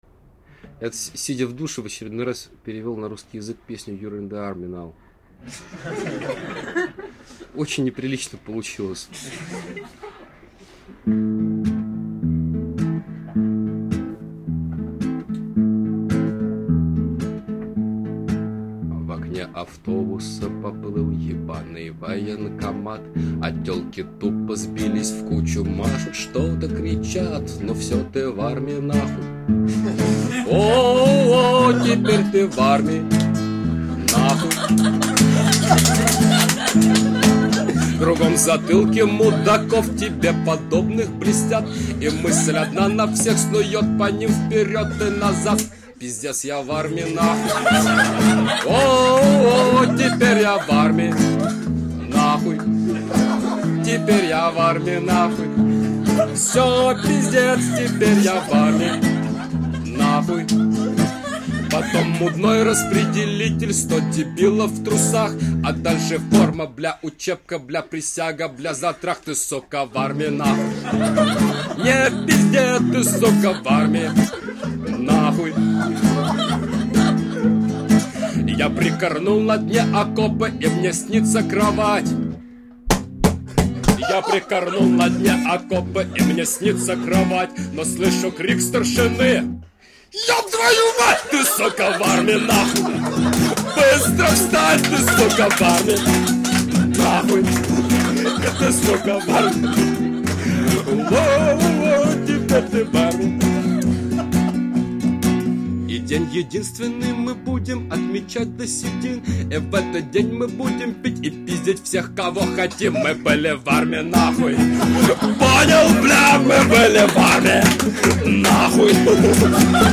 s_matami___pesni_pod_gita_.mp3